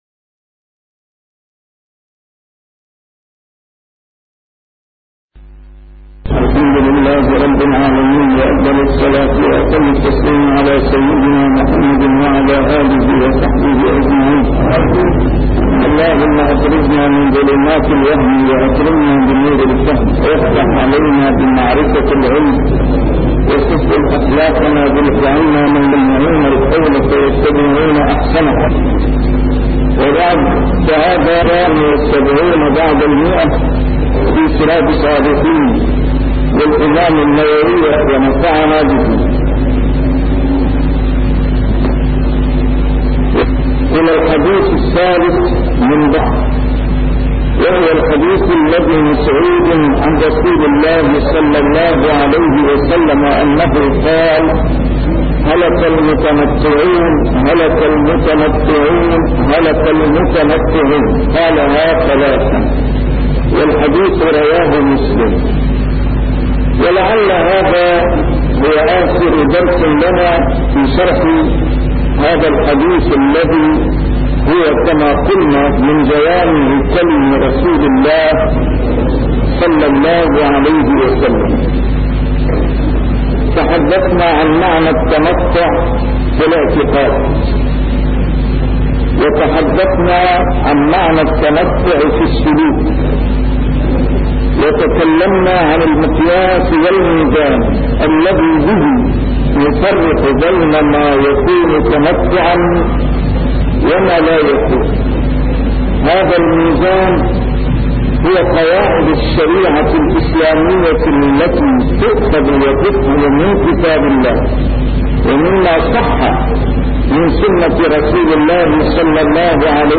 A MARTYR SCHOLAR: IMAM MUHAMMAD SAEED RAMADAN AL-BOUTI - الدروس العلمية - شرح كتاب رياض الصالحين - 178- شرح رياض الصالحين: الاقتصاد في العبادة